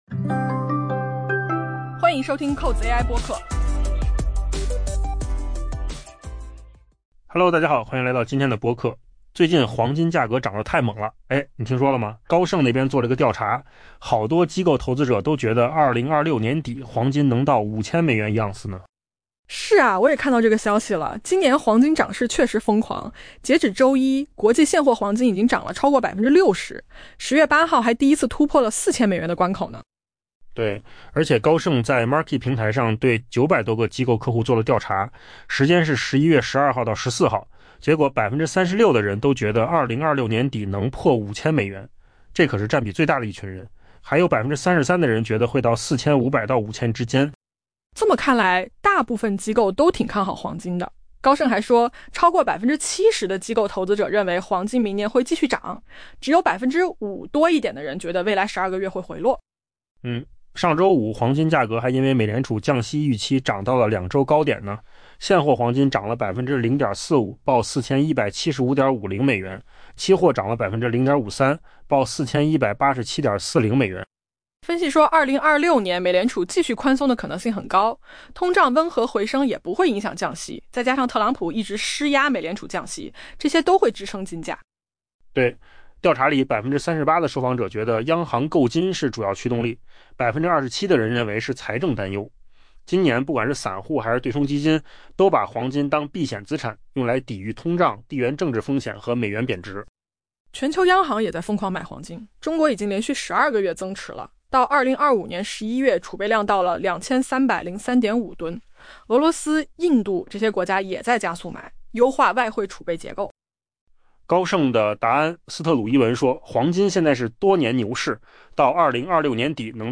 AI 播客：换个方式听新闻 下载 mp3 音频由扣子空间生成 黄金今年迎来了疯狂涨势。